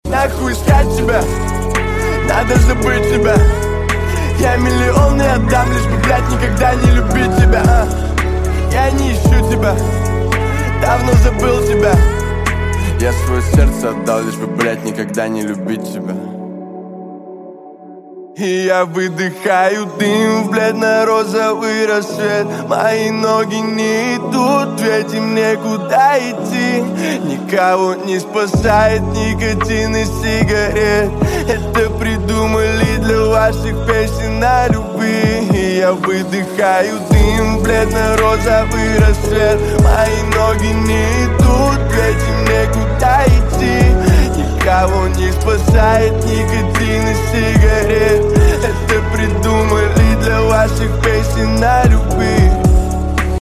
Грустные
Рэп Хип-Хоп